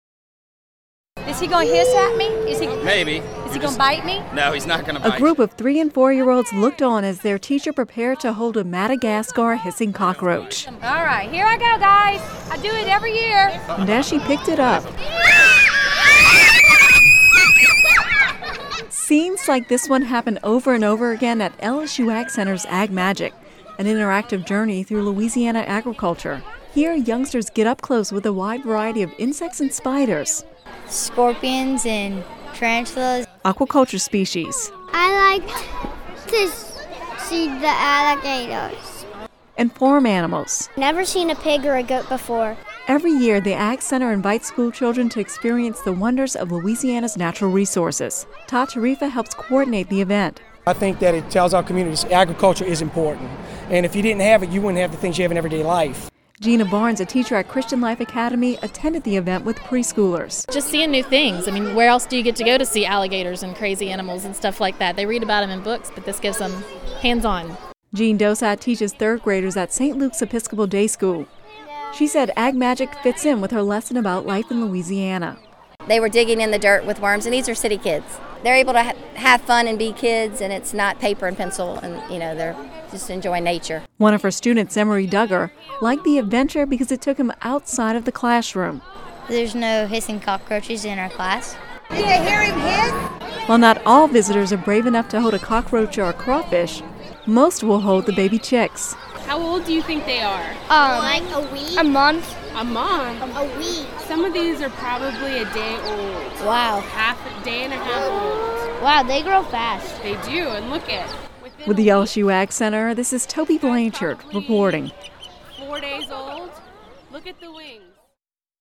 (Radio News 05/05/11) Three and four year olds looked on as their teacher prepared to hold a Madagascar hissing cockroach. As she picked it up, the youngsters shrieked with delight. Scenes like this one happen over and over again at the LSU AgCenter’s AgMagic, an interactive journey through Louisiana agriculture.